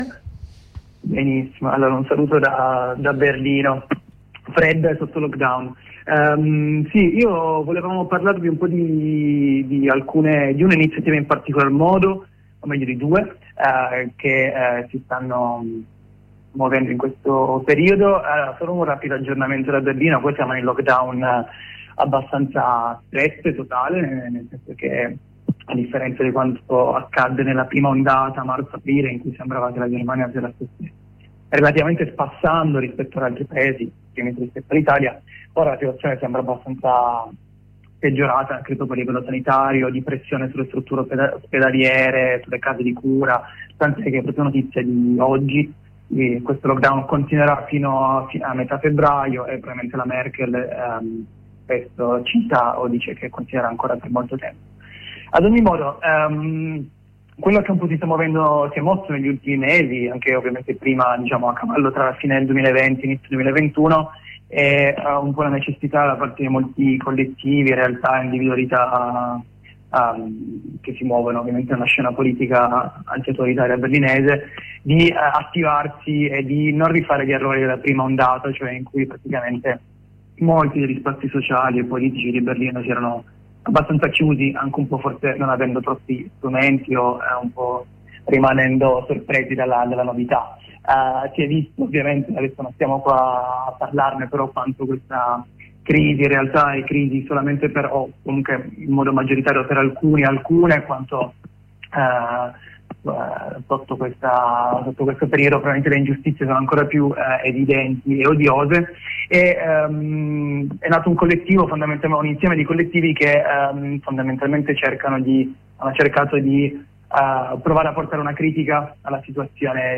Questo sabato ci sarà un corteo che ha l’obiettivo di far emergere tutte le criticità che la pandemia ha acuito, e vuole essere un importante passo collettivo dei movimenti berlinesi per trovare una quadra tra le lotte da portare avanti, le limitazioni del lockdown e l’autotutela collettiva. Ne abbiamo parlato con un compagno che si trova a Berlino.